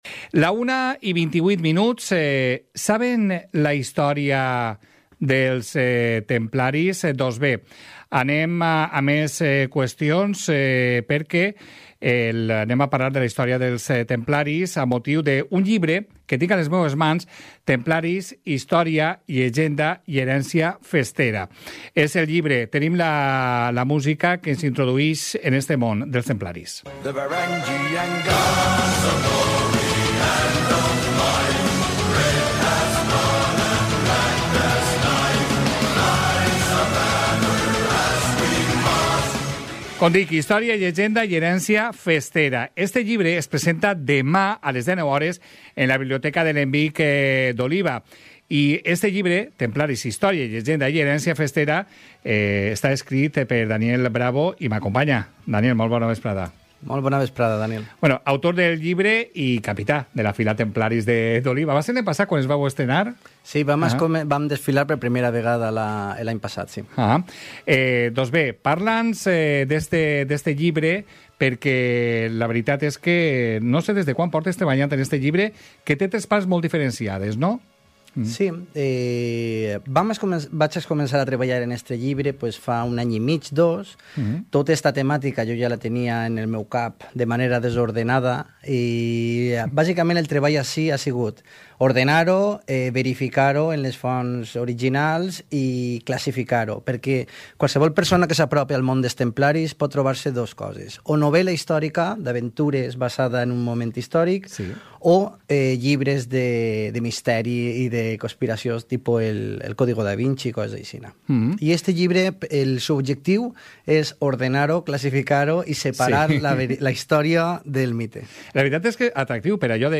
Lo hemos entrevistado hoy en el programa "Hoy por Hoy Gandia".